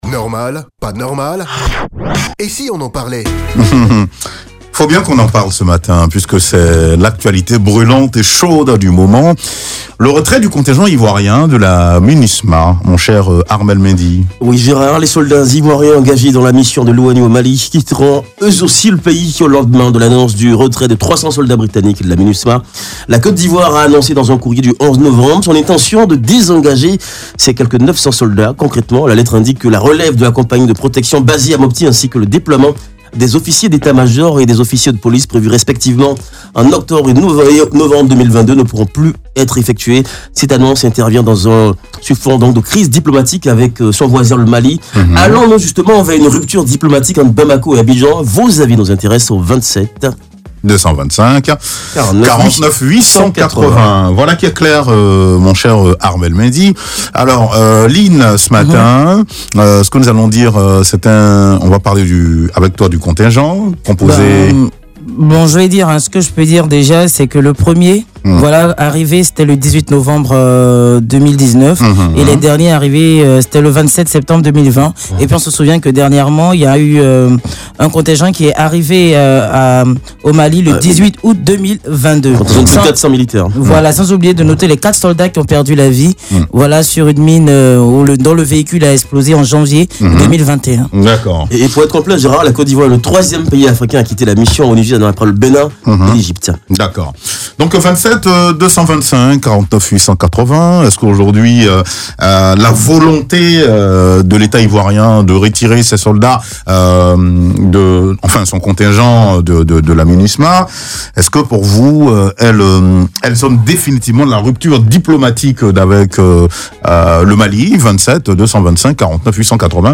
Les auditeurs de Life Radio donnent leurs avis.